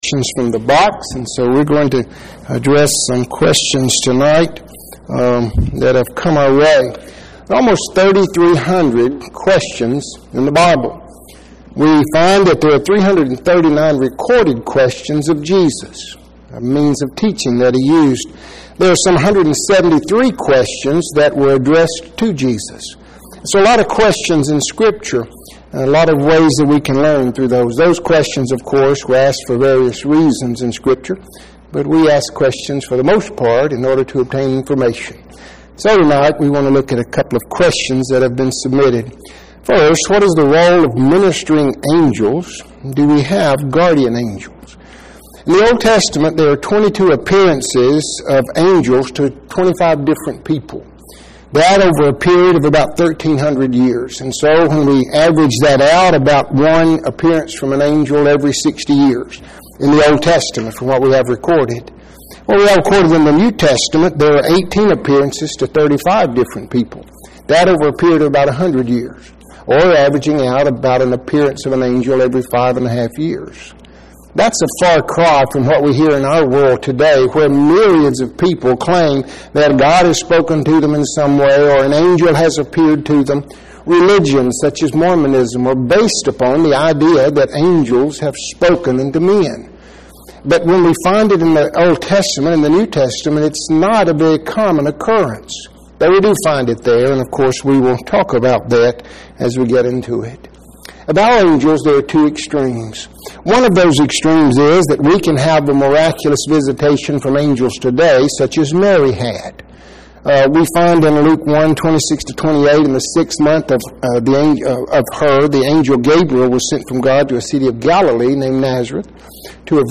Qestion and Answer